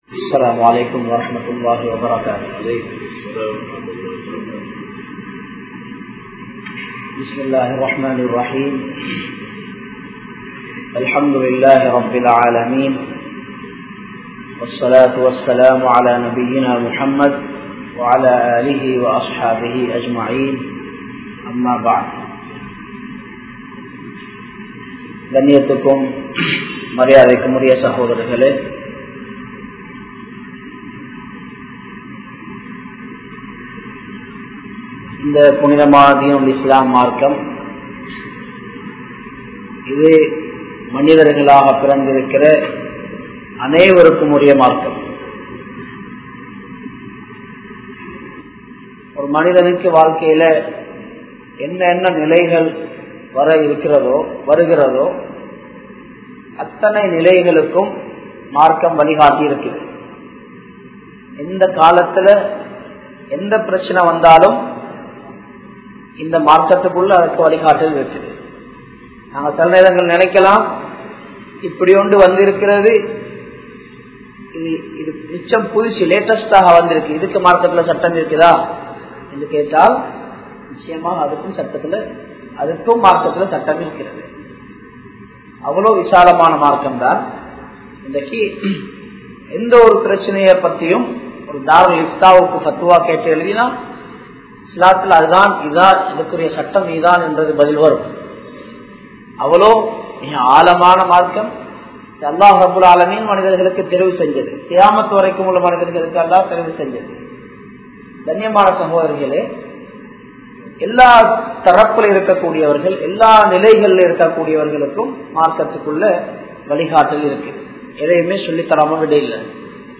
Amarnthu Tholuvathan Sattangal(அமர்ந்து தொழுவதன் சட்டங்கள்) | Audio Bayans | All Ceylon Muslim Youth Community | Addalaichenai
Colombo 12, Aluthkade, Muhiyadeen Jumua Masjidh